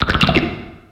Cri de Nodulithe dans Pokémon X et Y.